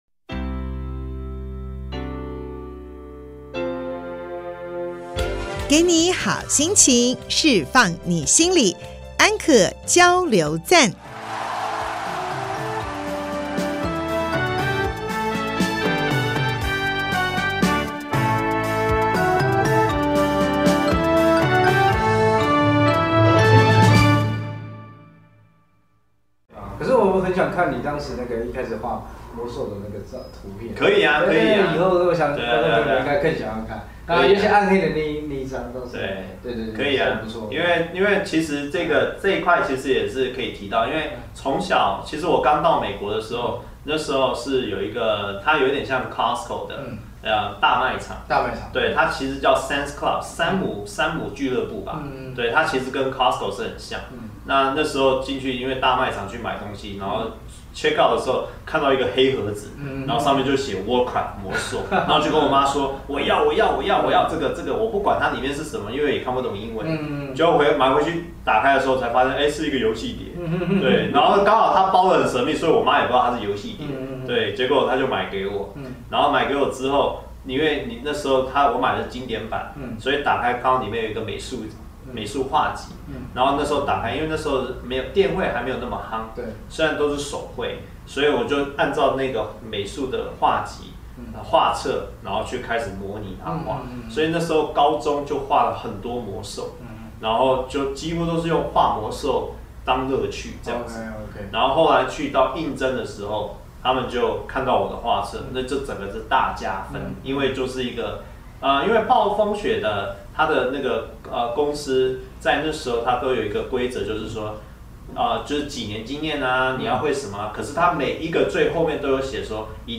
節目裡有生活點滴的分享、各界專業人物的心靈層面探析及人物專訪，比傳統心理節目多加了歷史人物與音樂知識穿插，更為生動有趣，陪伴您度過深夜時光，帶來一週飽滿的智慧與正能量。